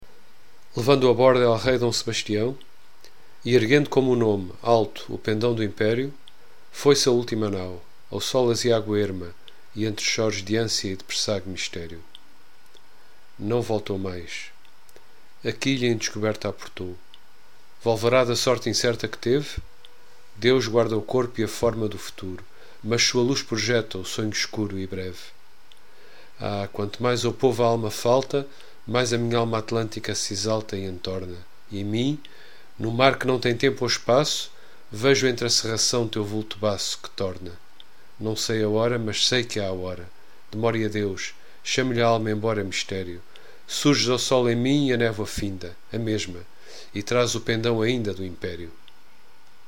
Index of poems with explanation and readings